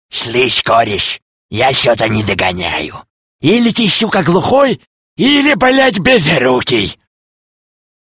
смешные
голосовые